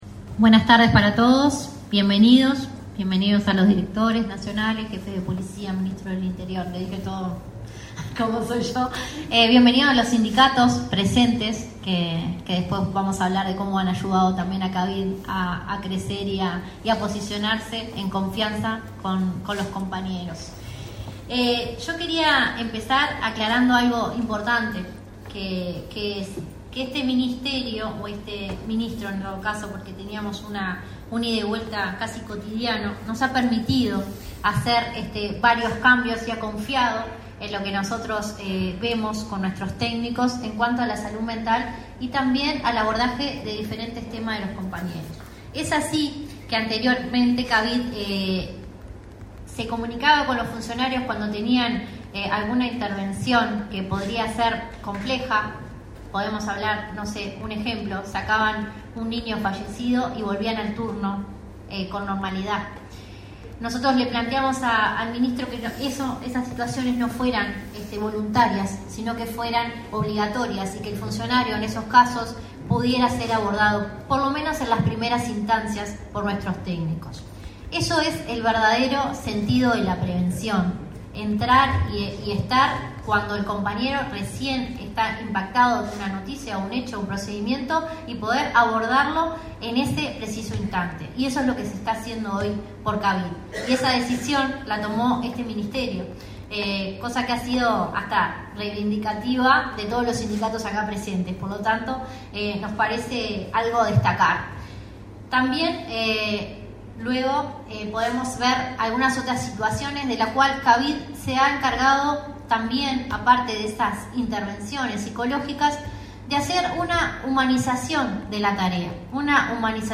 Acto de rendición anual del Centro de Atención a las Víctimas de la Violencia y el Delito